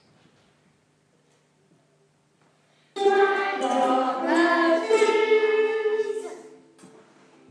Our tuning song!